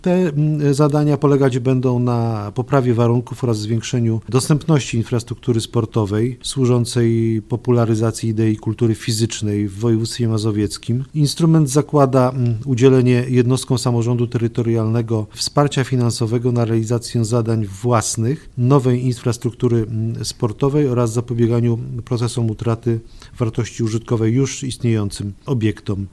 Mówi wicemarszałek województwa mazowieckiego Wiesław Raboszuk: